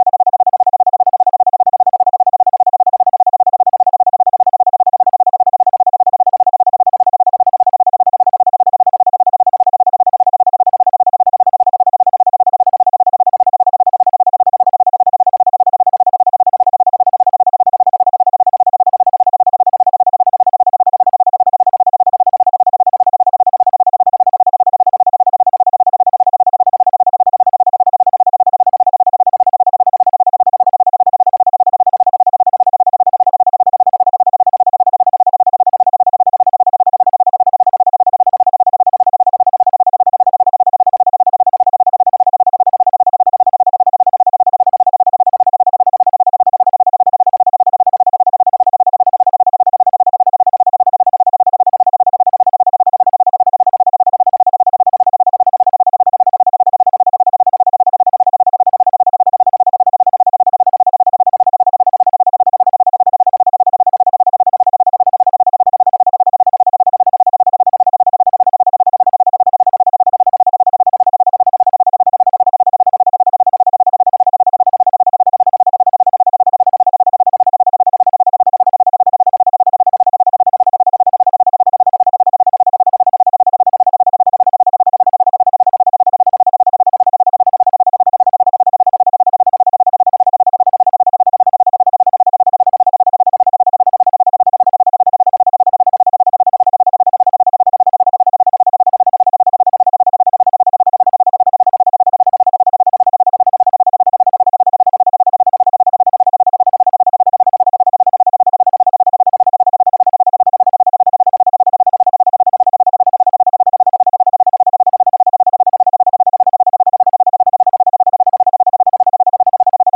Isochronic Tones
Brain-Boost-Focus-Track-02-online-audio-converter.com_.mp3